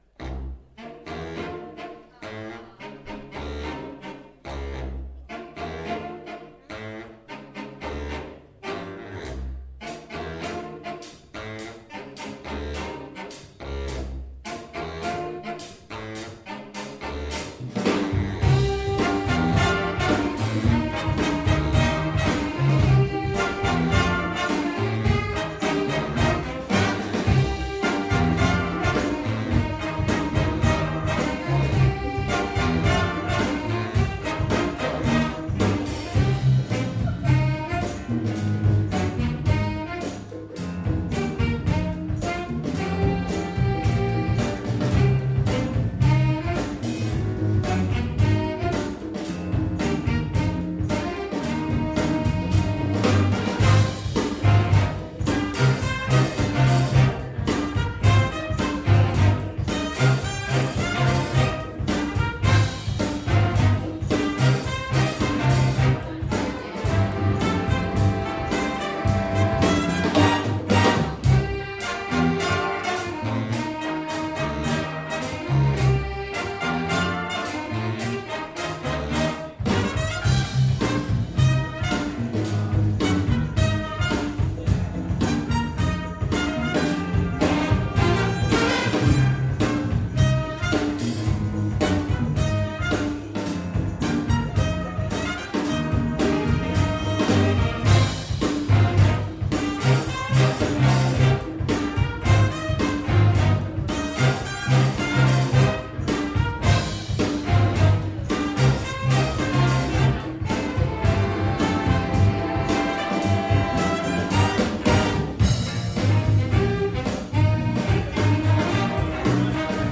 From the Big Band Evening March 2017